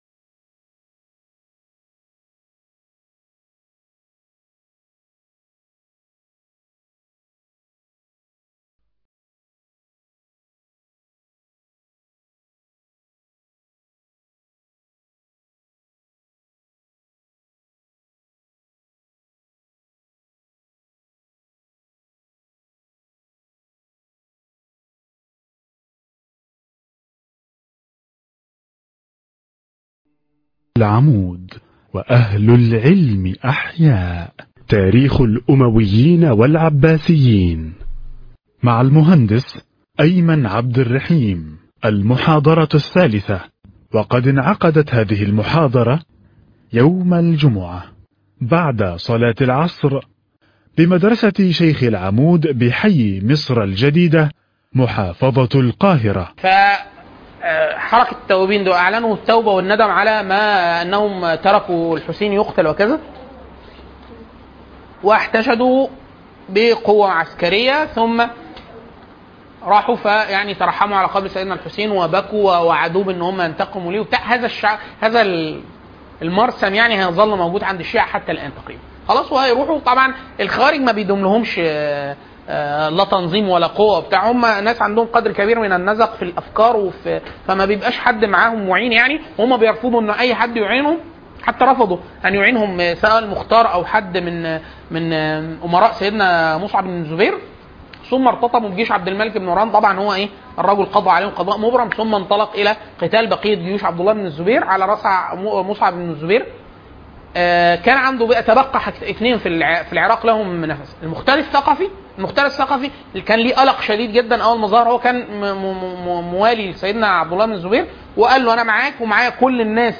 تاريخ الدولة الأموية والعباسية (المحاضرة الثالثة